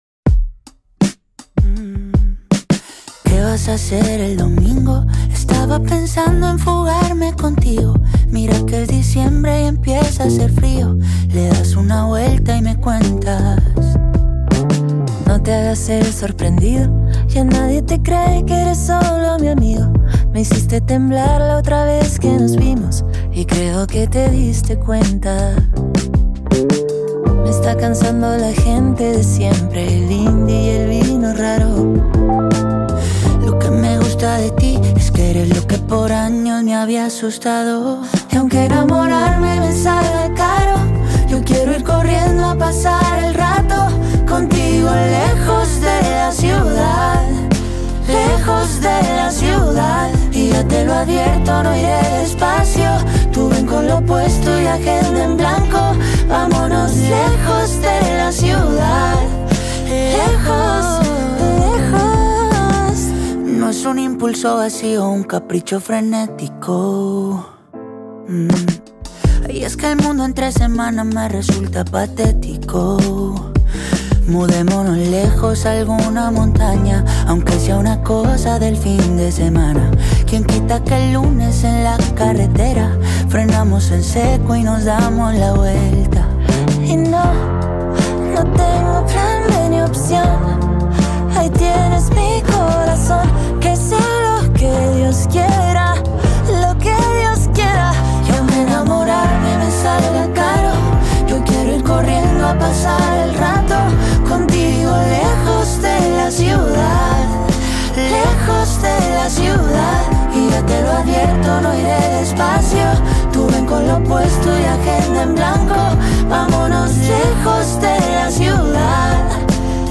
a mesmerising melody